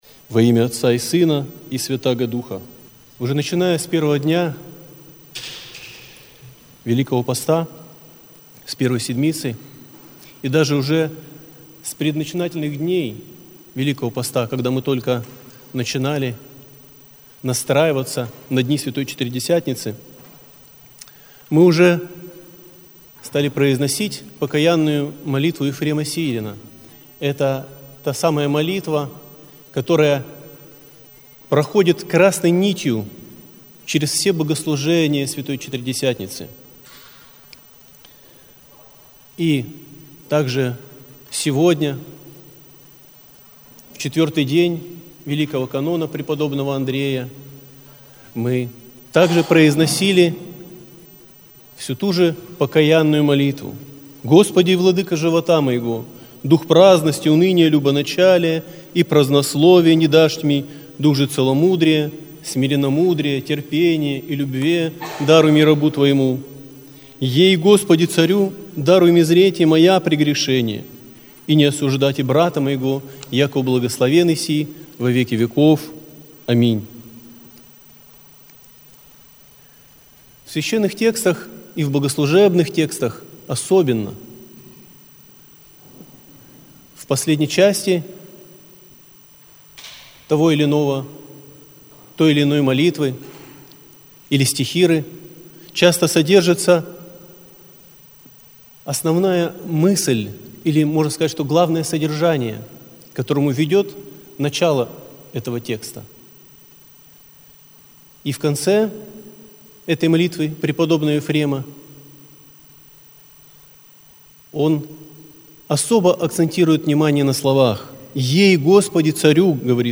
Аудиозапись слова